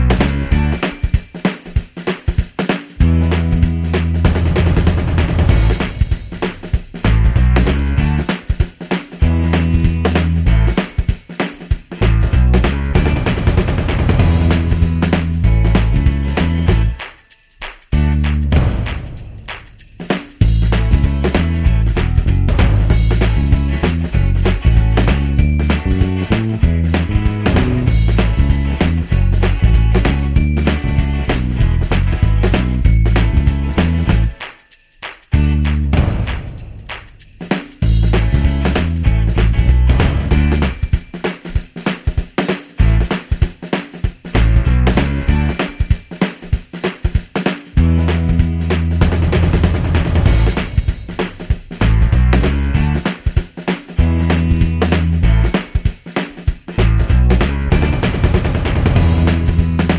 no Backing Vocals Glam Rock 2:28 Buy £1.50